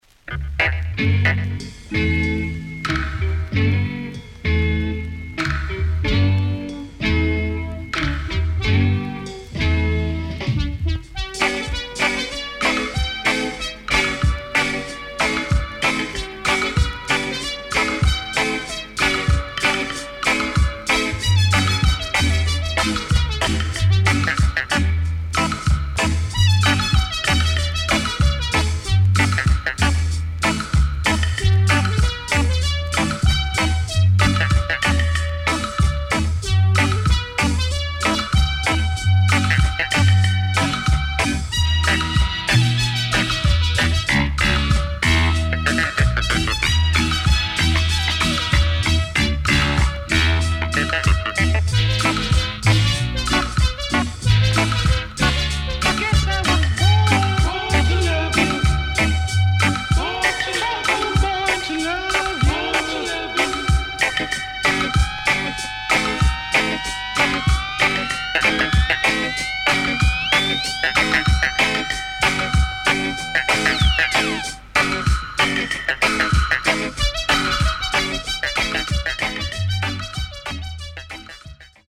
SIDE A:軽いヒスノイズ入りますが良好です。